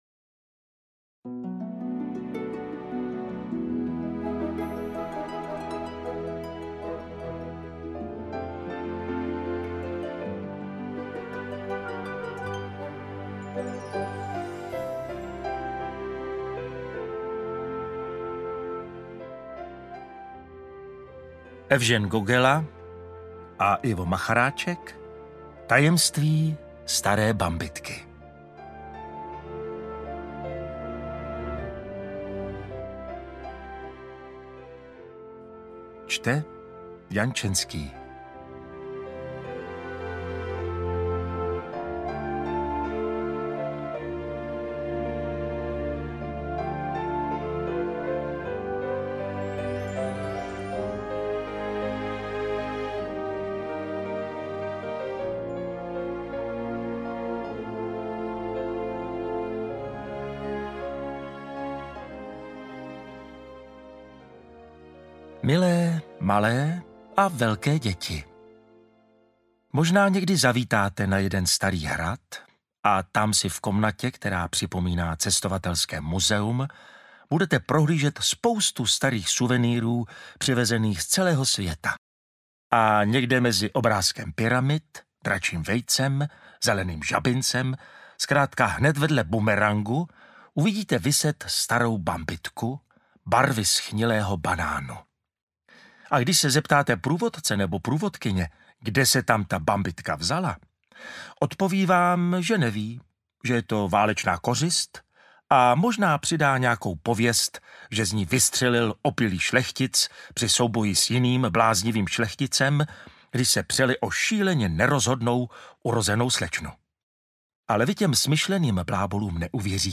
Interpret:  Jan Čenský
Původní hudba a písně skvěle doplňují atmosféru vyprávění, vytvořeného podle oblíbené filmové pohádky.
AudioKniha ke stažení, 14 x mp3, délka 3 hod. 37 min., velikost 197,4 MB, česky